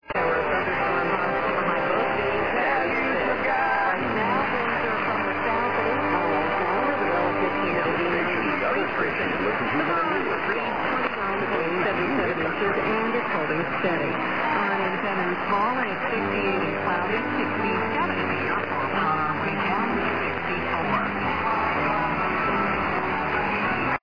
Here's some clips of the station recorded before midnight:
And, does he say "King", right at the end?